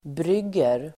Uttal: [br'yg:er]